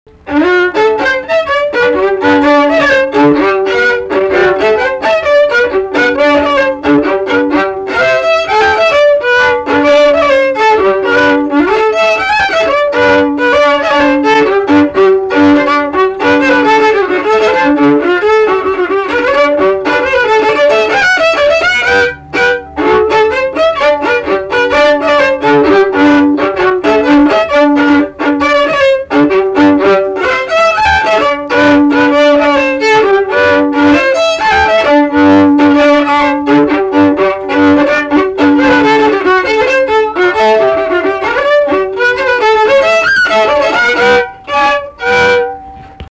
šokis
Luokė
instrumentinis
smuikas